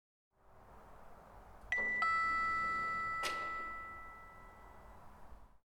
Elevator_DingDong
arriving ding door elevator lift open sound effect free sound royalty free Sound Effects